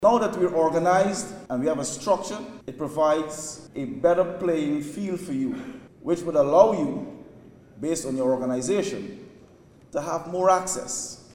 The India/Guyana Chamber of Commerce (IGCC) convened its inaugural networking event on Wednesday evening at the Marriott Hotel, bringing together key stakeholders to explore investment opportunities.